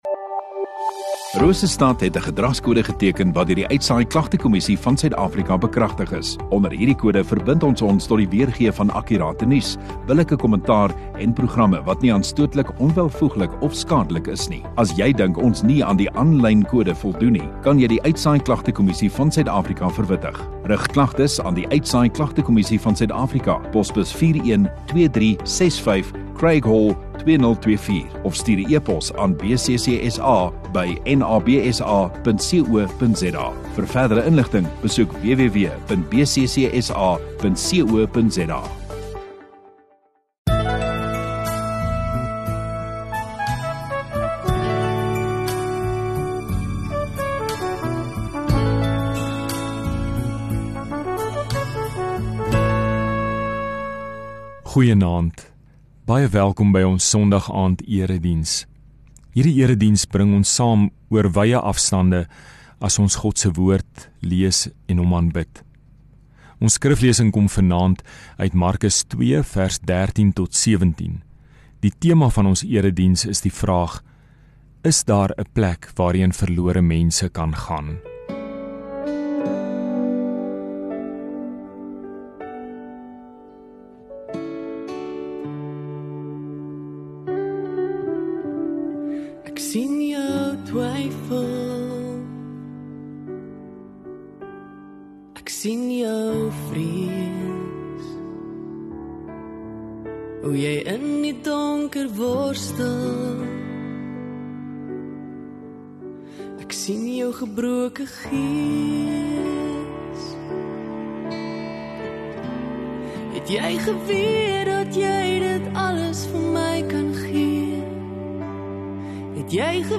8 Sep Sondagaand Erediens